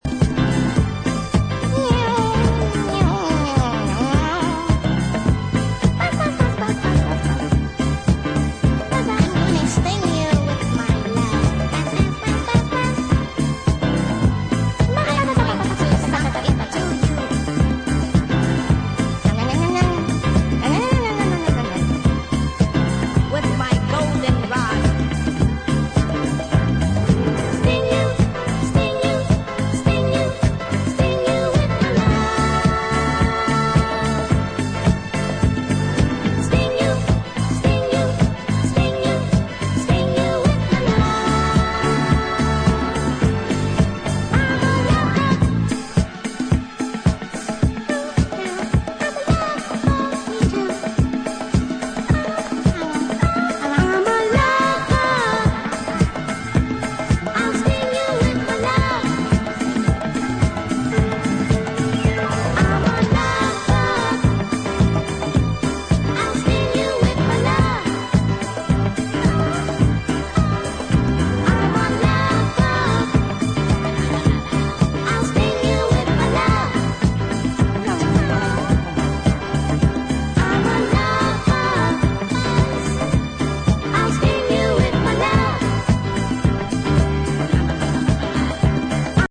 Irish Dance
remastered tracks